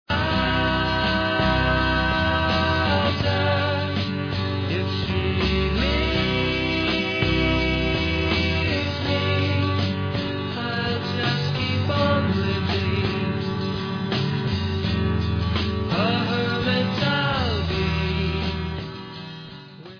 Sixties psychedelia, could be on the "nuggets" box